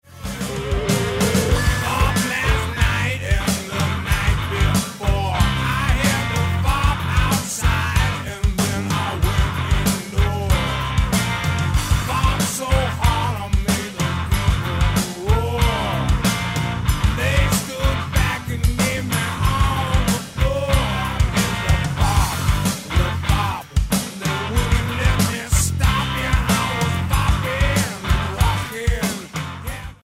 vocals
guitar
drums
bass